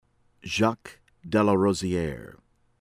DELANOE, BERTRAND beh-TRAH (n)    deh-lah-noh-EE